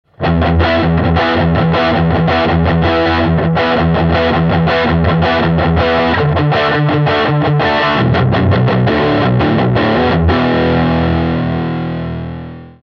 On peut cependant dire qu’il s’agit de sonorités allant de l’overdrive à la belle distorsion, plutôt moelleuses, rondes, pleines de basses (ici et ici), et toujours empruntes du dynamisme propre à Fender,
J’ai choisi de rester sur la Les Paul pour tester ces voicings, afin de voir la réaction de l’ampli face à un instrument possédant un haut niveau de sortie. On notera sur le dernier exemple qu’il ne faudrait pas pousser plus les basses, le HP risquant de montrer ses limites.
lespaul+voicing12.mp3